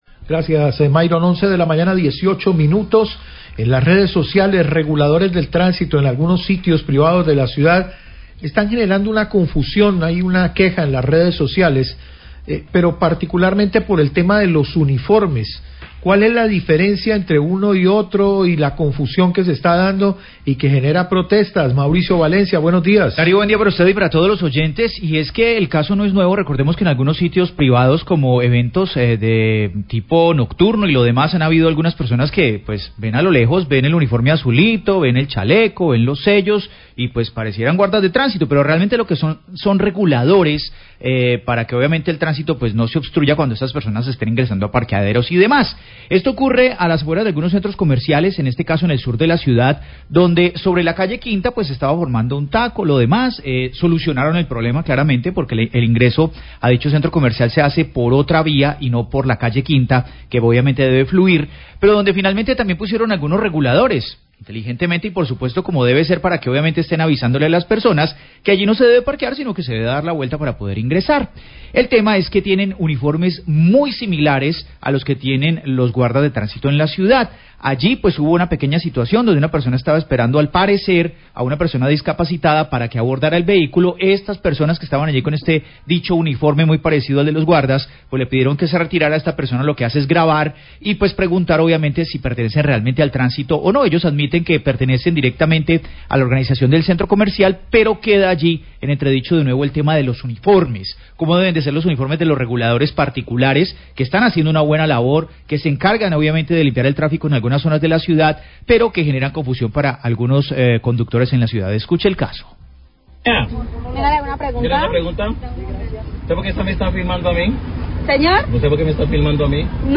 Periodistas comentan sobre confusión por similitud uniformes guardas de tránsito y reguladores privados
Radio